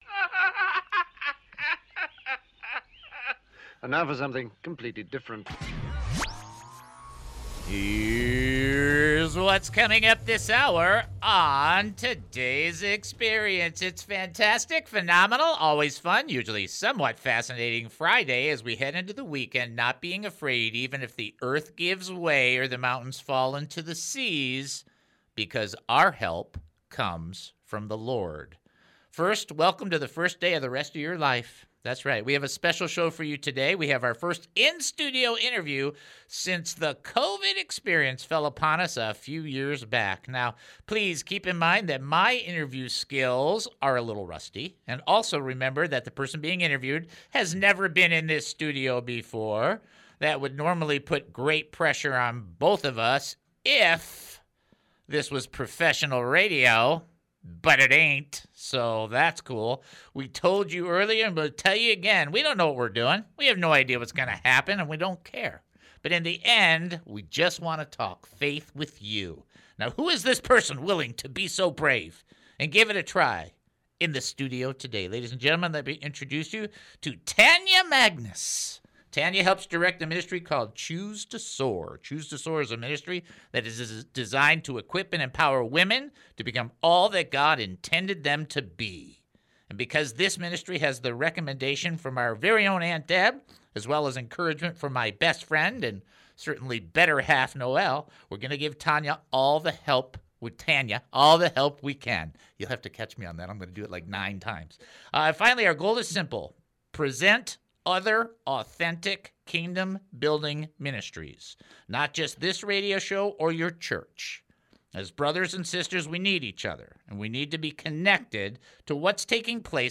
We have our first in-studio interview since the COVID experience fell upon us a few years back.